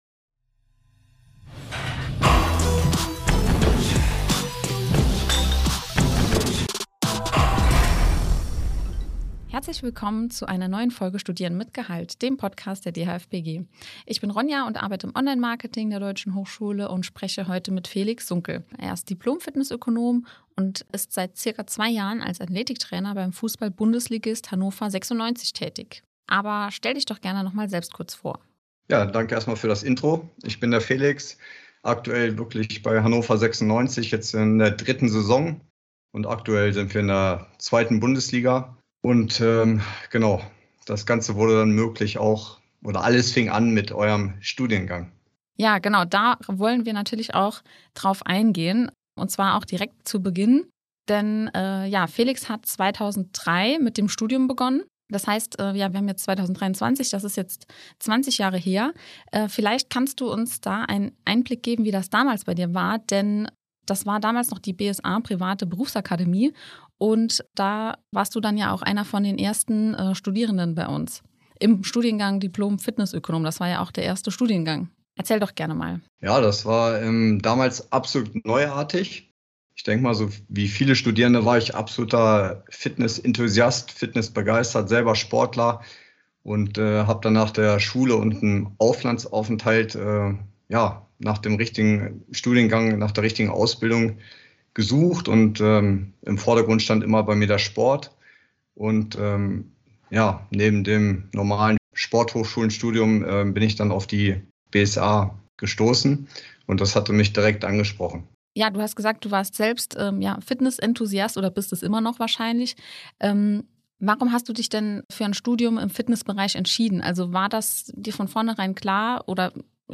Wir haben mit ihm gesprochen!